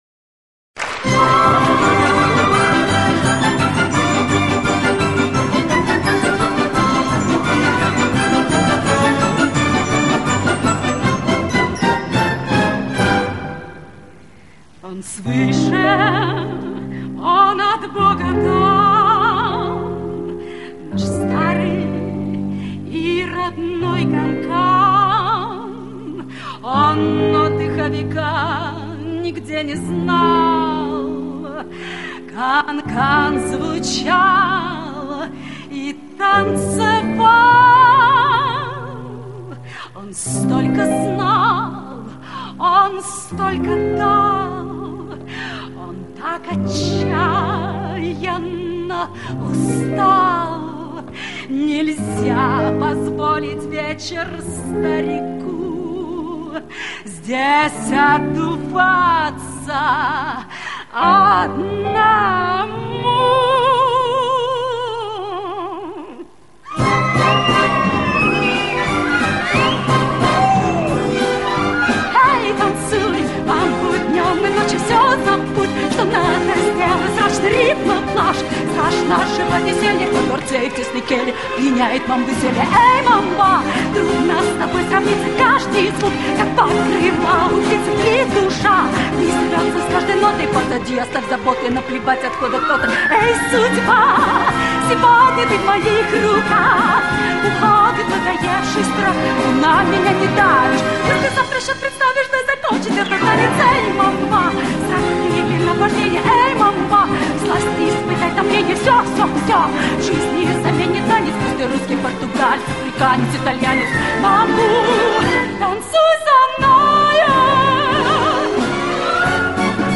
Там пела женщина с группой.